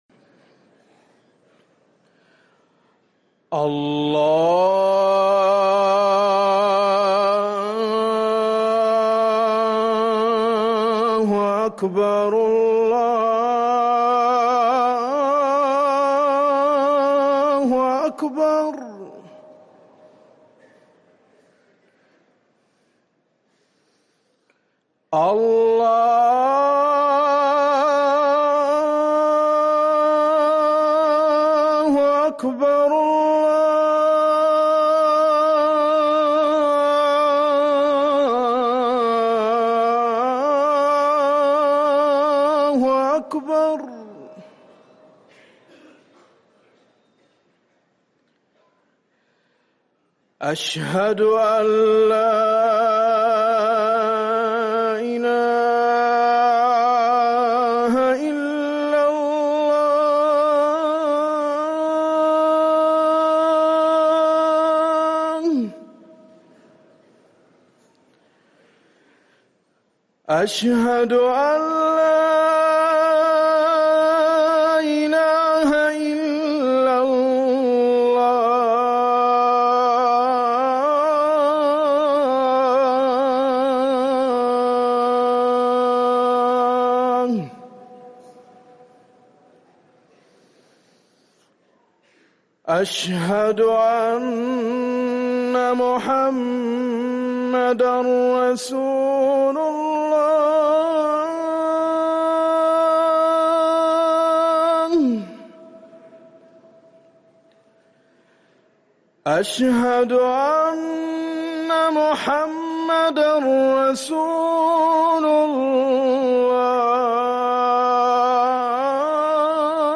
اذان الظهر